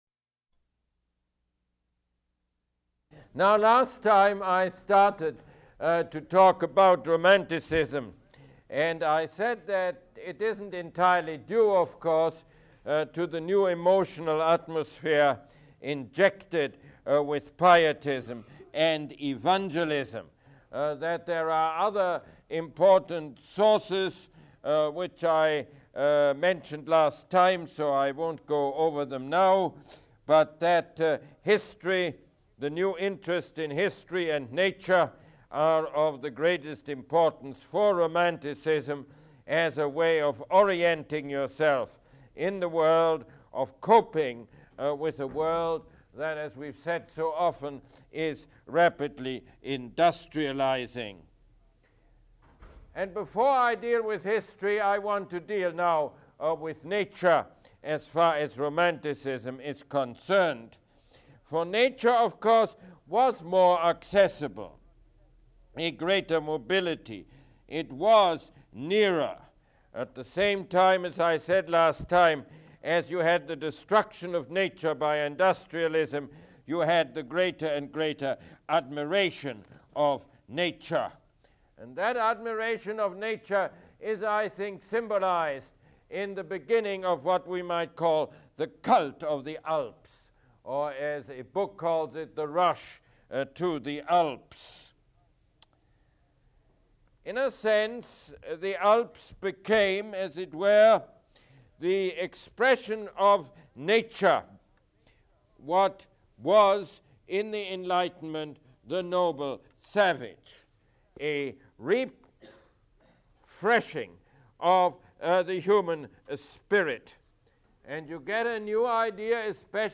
Mosse Lecture #13